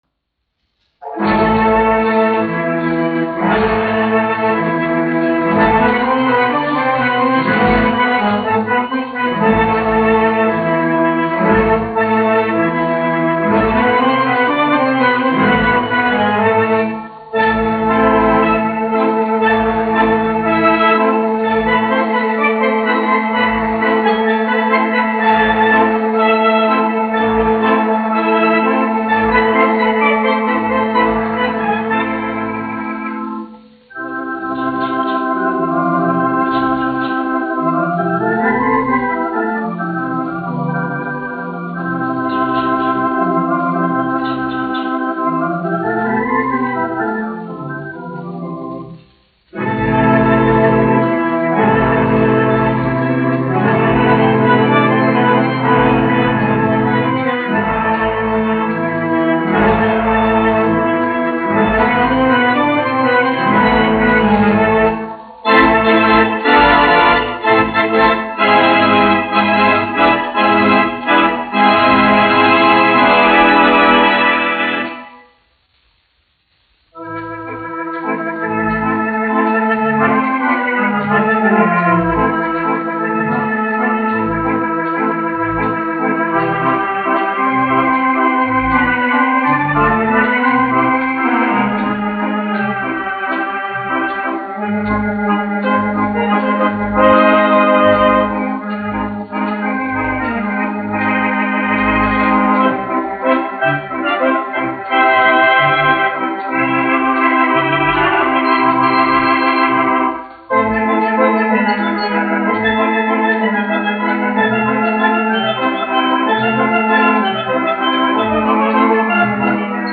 1 skpl. : analogs, 78 apgr/min, mono ; 30 cm
Ērģeļu mūzika, aranžējumi
Latvijas vēsturiskie šellaka skaņuplašu ieraksti (Kolekcija)